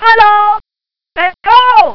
I used "microcomputer control mode" to play each of the 8 samples while capturing the data at the two PWM outputs, then converted them to WAV format: Sound 0 Sound 1